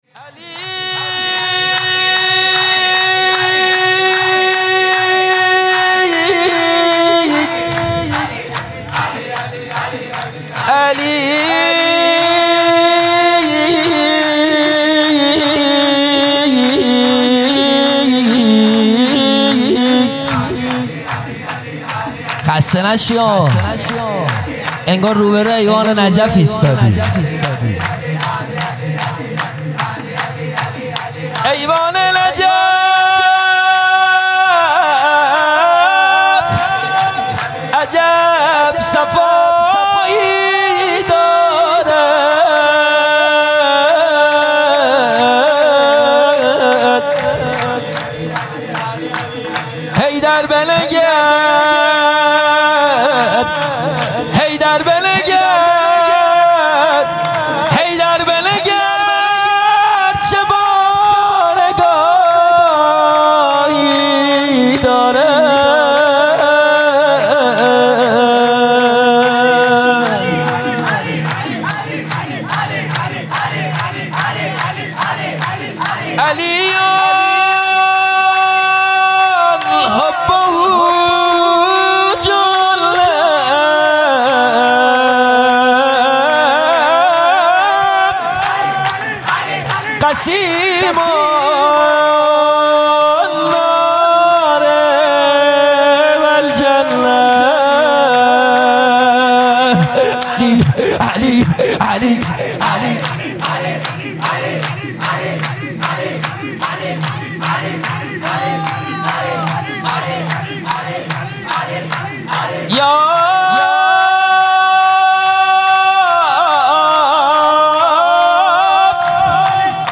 سرود | ایوان نجف عجب صفایی دارد
جلسۀ هفتگی (به مناسبت ولادت رسول اکرم(ص) و امام صادق(ع) | به یاد شهید تهرانی مقدم | 23 آبان 1398